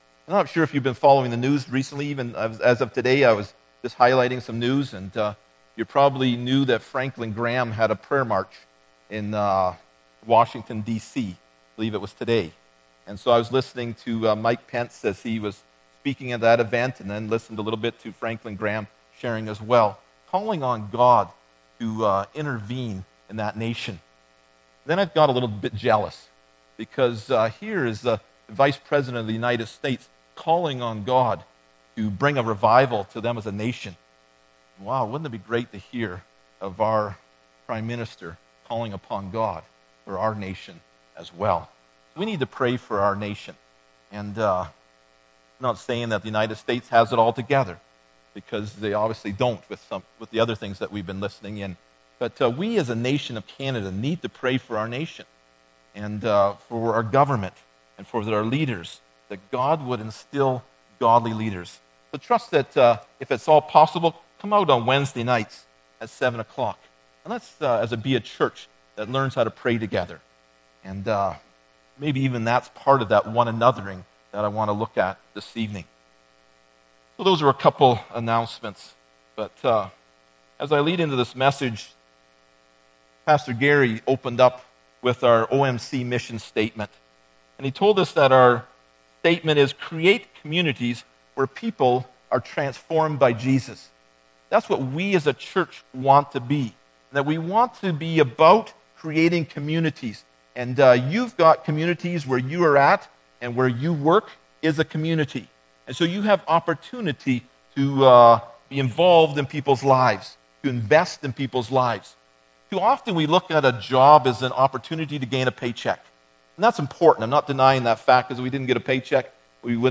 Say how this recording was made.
Phil 2:3 Service Type: Sunday Morning Bible Text